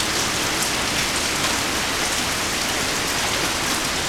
REGEN.mp3